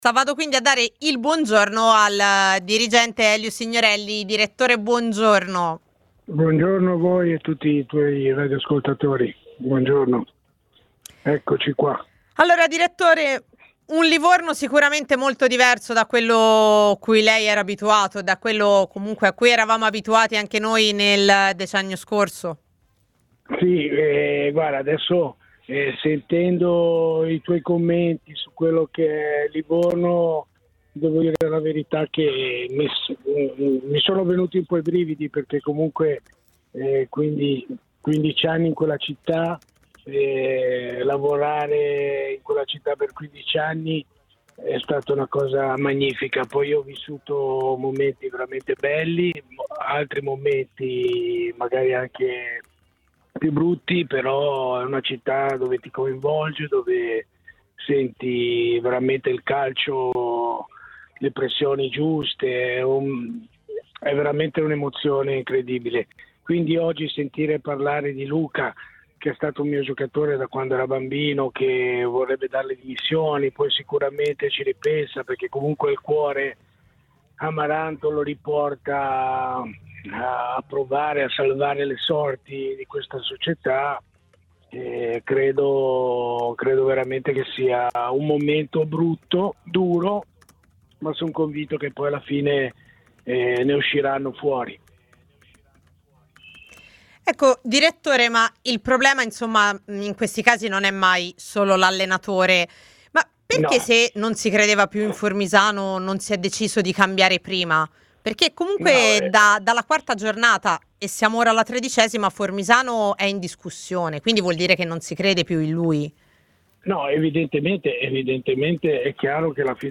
è intervenuto nel corso di A Tutta C, su TMW Radio, per parlare della situazione del club toscano;